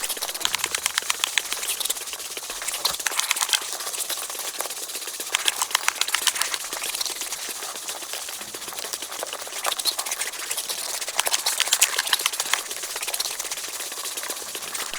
Sfx_creature_rockpuncher_chase_01.ogg